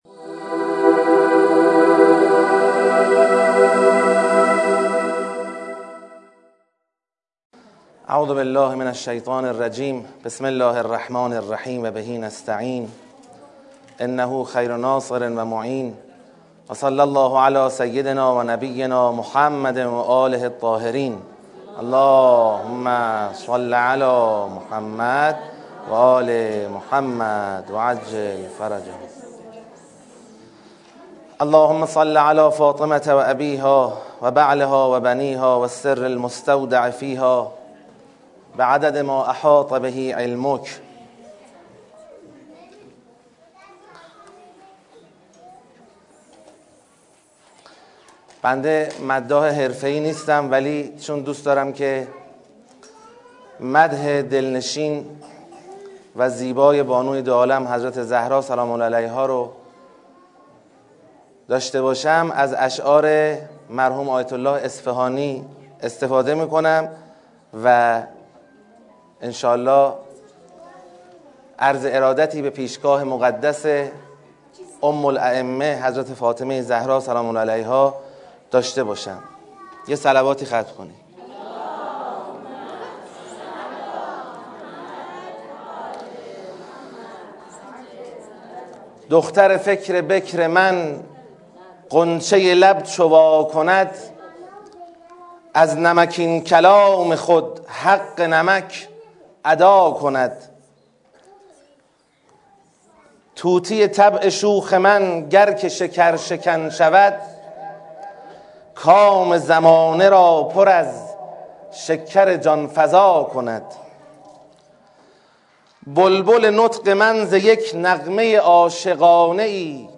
ویژه‌برنامۀ میلاد حضرت زهرا سلام‌الله‌علیها